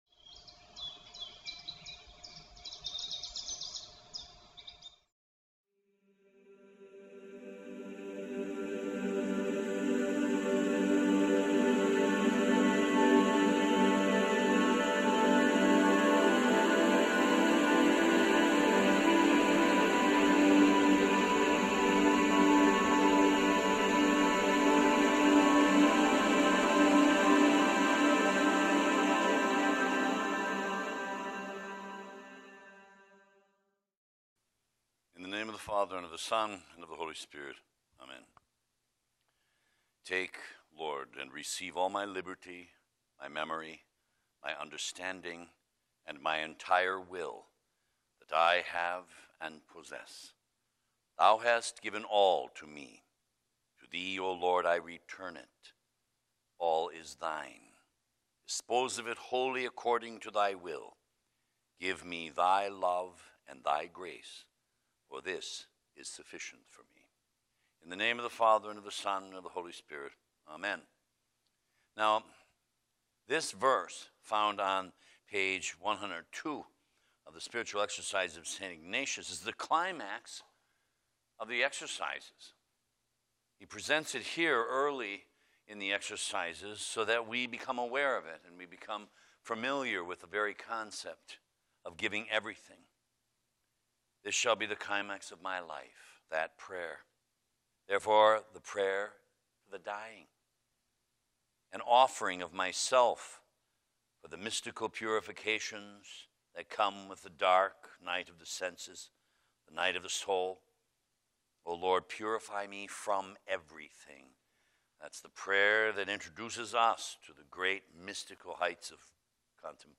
the seventh of twenty-five conferences for his Eight Day Ignatian Retreat. The subjects he covers are: God’s active Love for us and our response. How Our Lord must purify us of everything. Love of God and How to attain it.